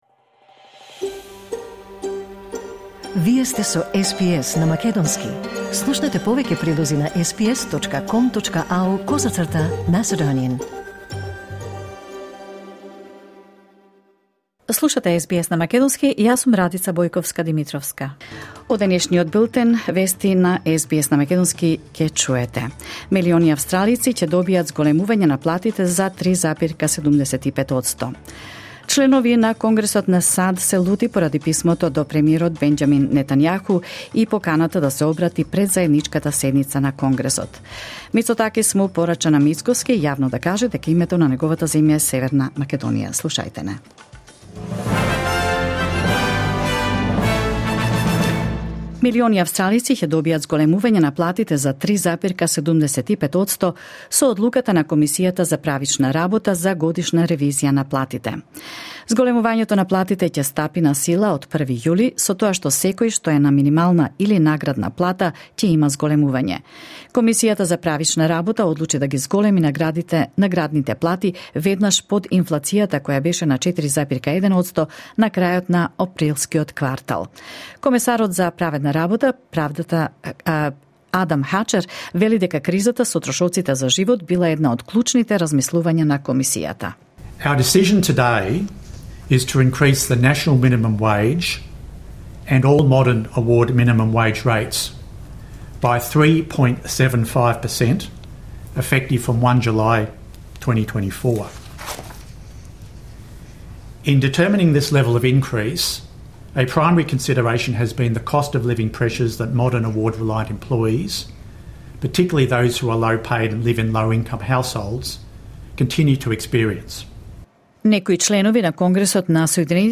SBS News in Macedonian 3 June 2024